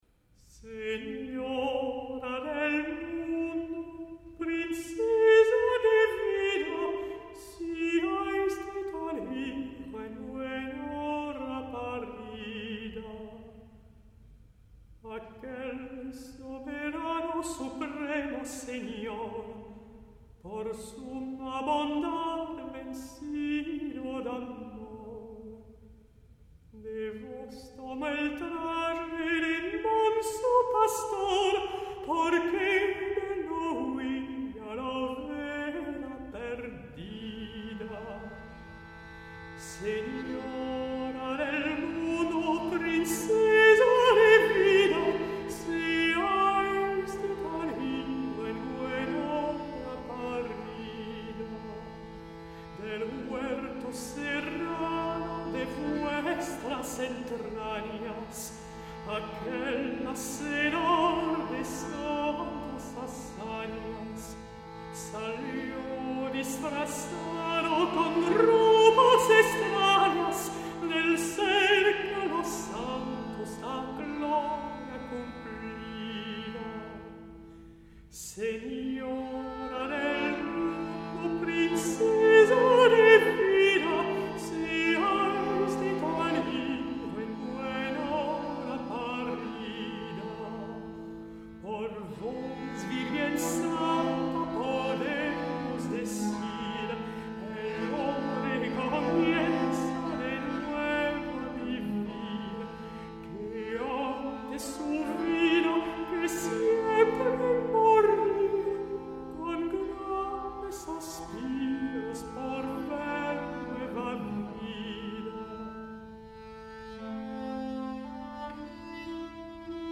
Tέτοια πηδήματα αναδεικνύουν τη συγχορδιακή αίσθηση ακόμα και σε μιά μονόφωνη μελωδία, όπως φαίνεται στο ακόλουθο ανώνυμο πορτογαλικό τραγούδι:
Παρεμπιπτόντως προσέξτε πως δεν υπάρχει καθόλου σι, πράγμα που όχι μόνο δεν μειώνει την αίσθηση ότι το κομμάτι είναι σε Δώριο, αλλά τουναντίον συνηγορεί με την έμφαση στις συγχορδίες Pε και Λα.